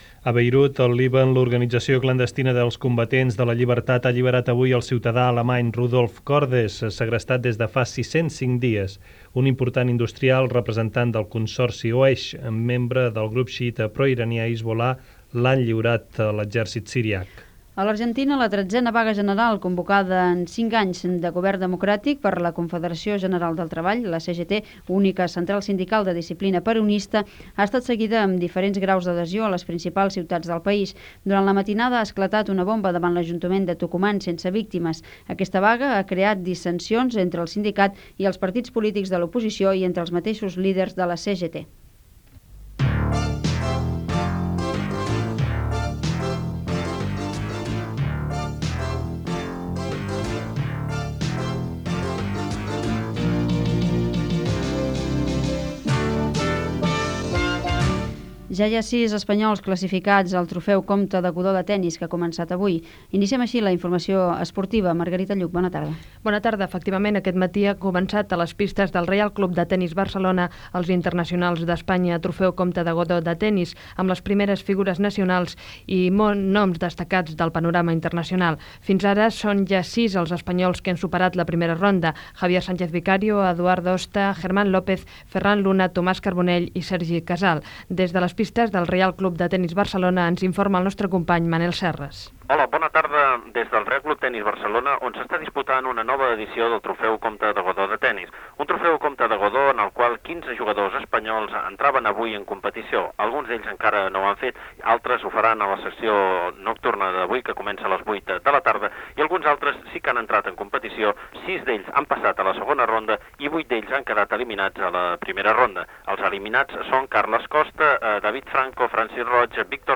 Informacions sobre: Líban, vaga general a Argentina, trofeu comte de Godó de Tennis, Jocs Olímpics de Seül. El temps i careta de sortida (veu Constantino Romero)
Informatiu